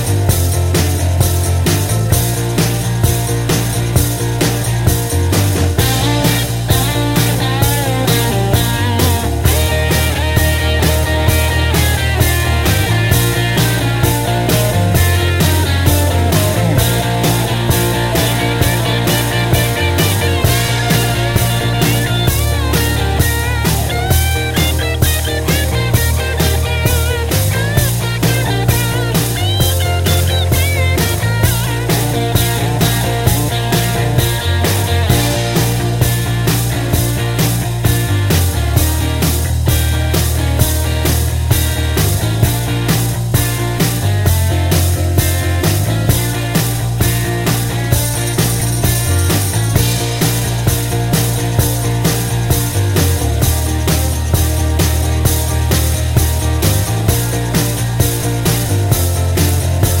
Down 2 Semitones No Backing Vocals Rock 3:11 Buy £1.50